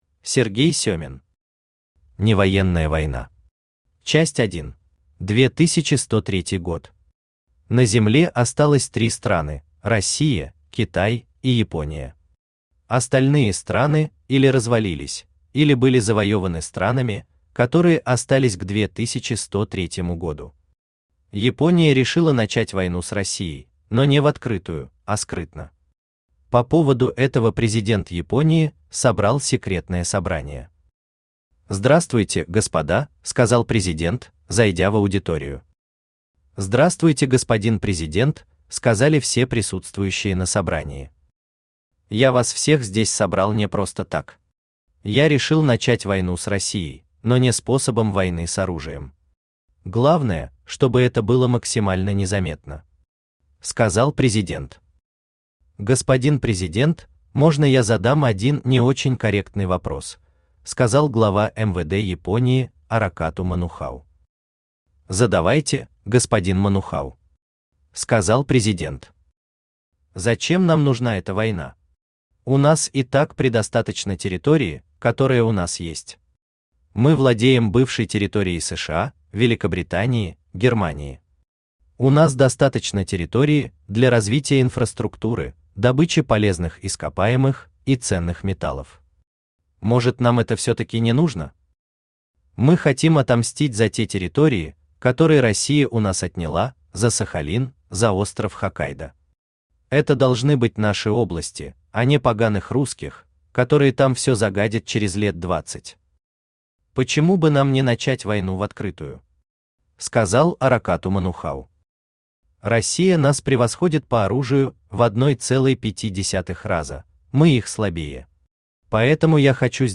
Аудиокнига Невоенная война. Часть 1 | Библиотека аудиокниг
Часть 1 Автор Сергей Владимирович Семин Читает аудиокнигу Авточтец ЛитРес.